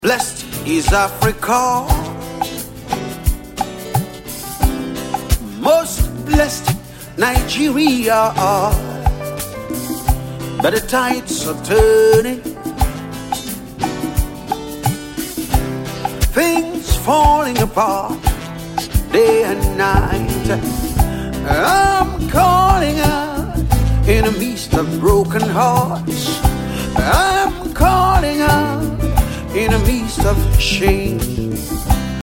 • Genre: Gospel